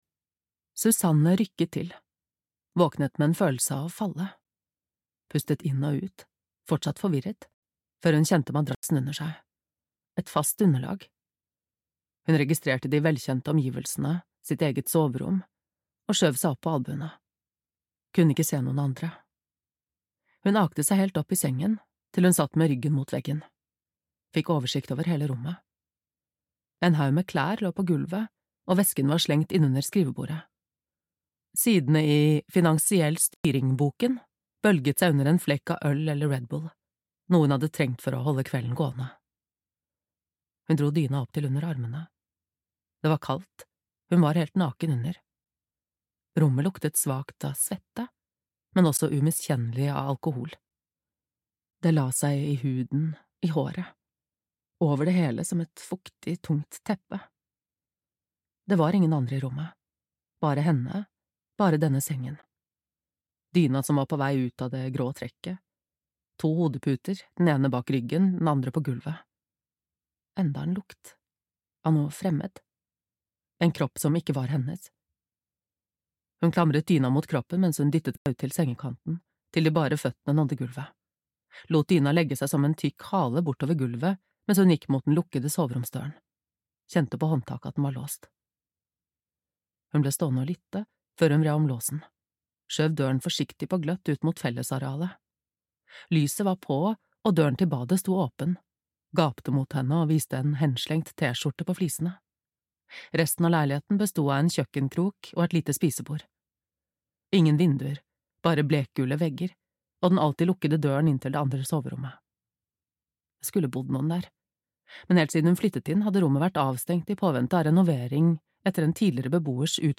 Menneskedyret - krim (lydbok) av Anette Hemming